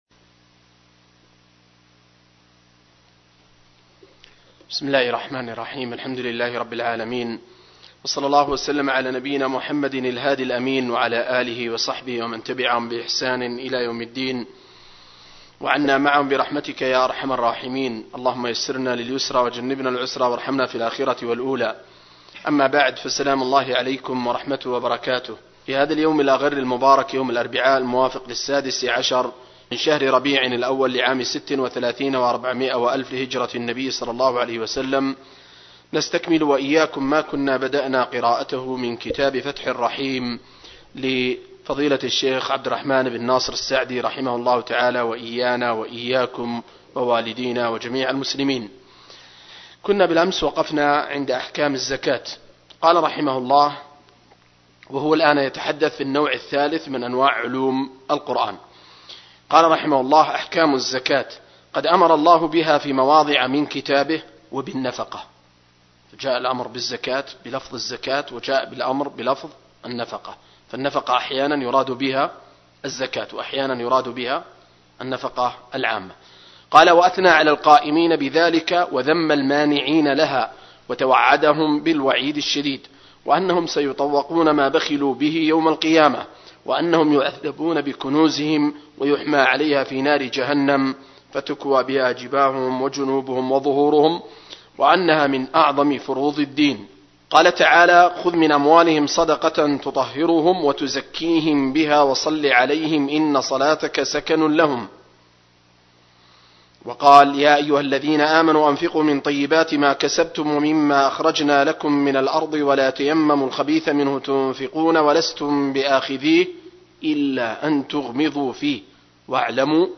دورة علمية في قاعة الدروس والمحاضرات